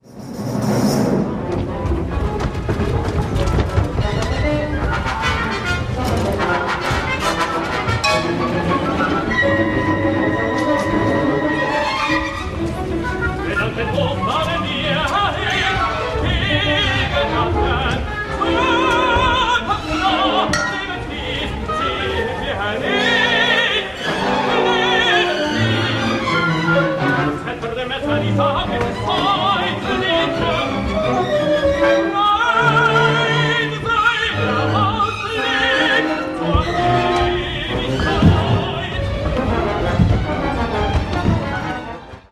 Junger Mann / Jüngling
Komische Oper